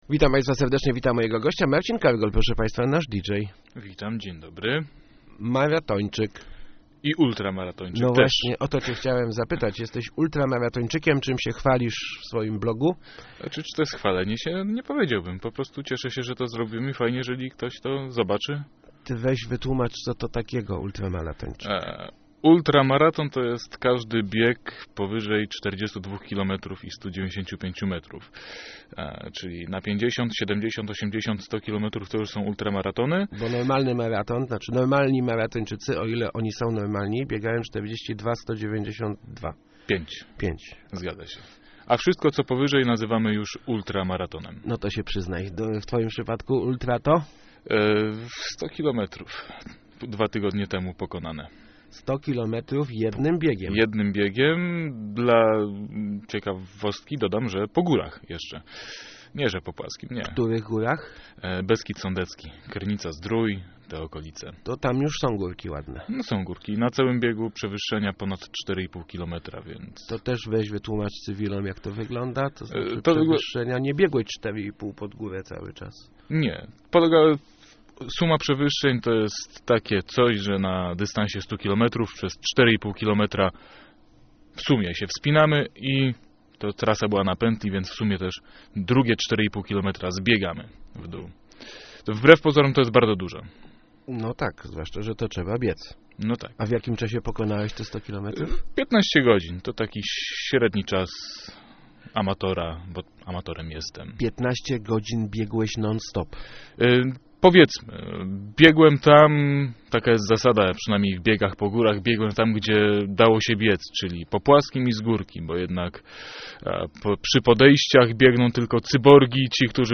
Start arrow Rozmowy Elki arrow Gdy maraton to za mało